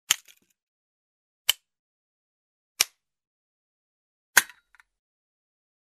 Egérfogó | Hanghatások letöltése .mp3.
Egérfogó | hanghatás .mp3 | Letöltés ingyen.